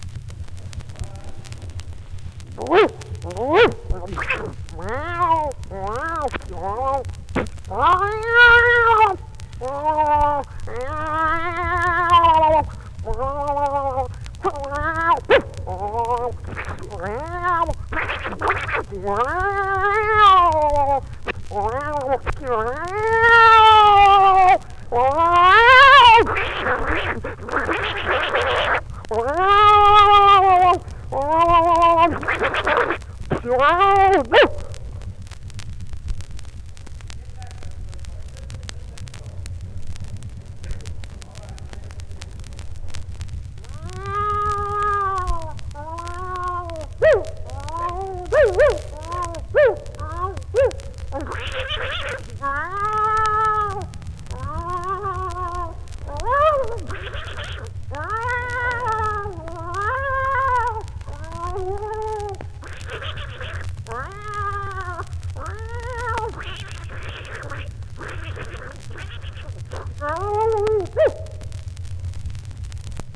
Imitations
模仿
How do you think he makes the sounds seem to overlap?
Southern Mosaic: The John and Ruby Lomax 1939 Southern States Recording Trip, Library of Congress.